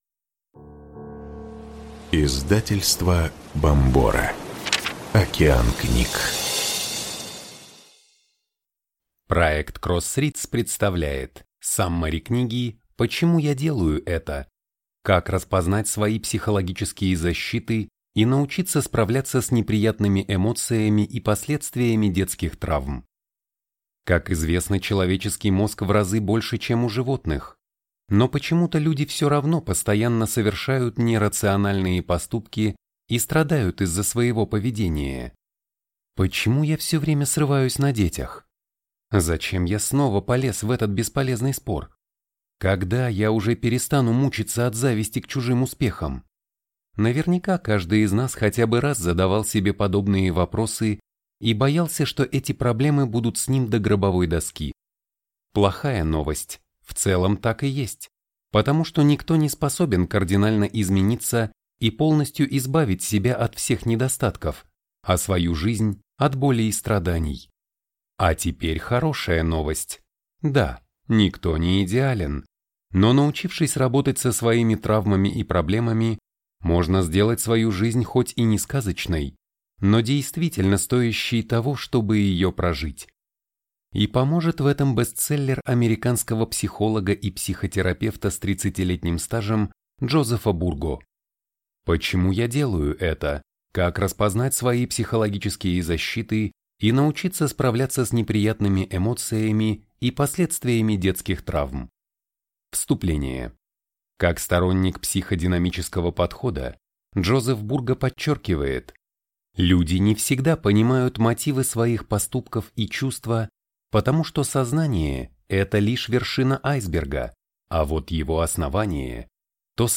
Аудиокнига Саммари книги «Почему я делаю это.